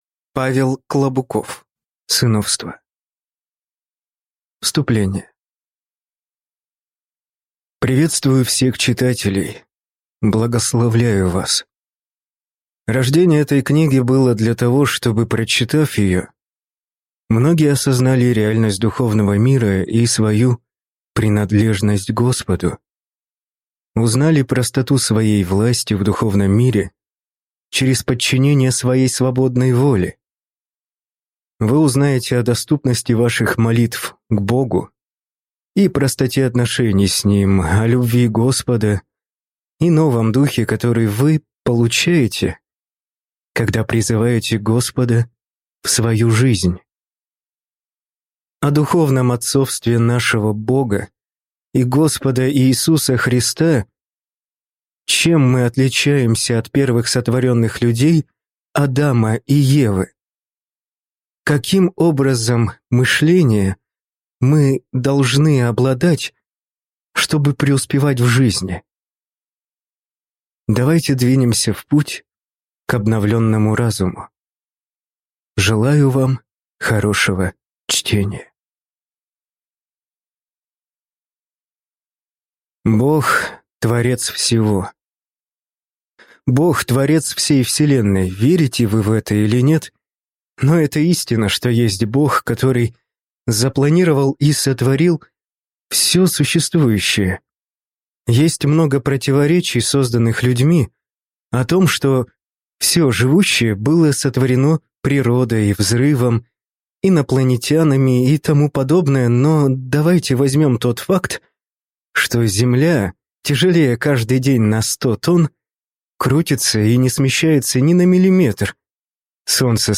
Аудиокнига Сыновство | Библиотека аудиокниг